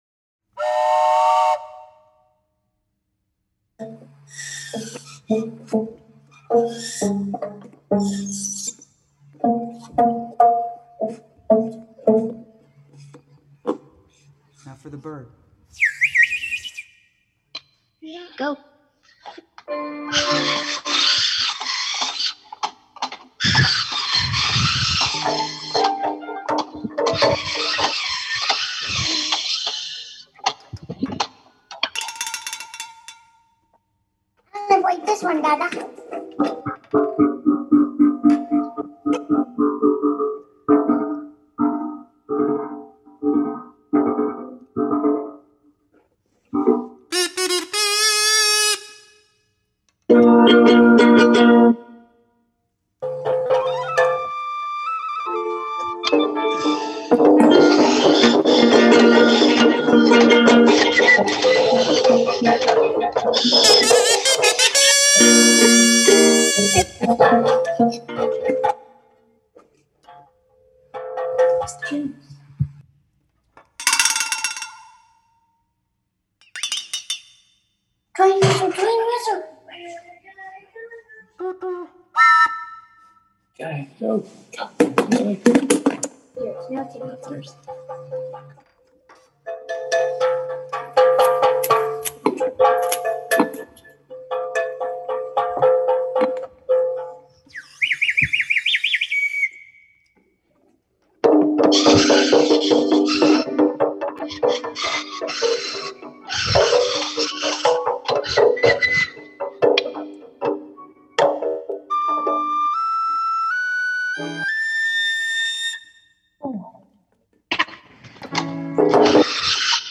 participants constructed their own instruments from household objects
the virtual ensemble in an improvised composition
Listen: Homemade instrument improvisation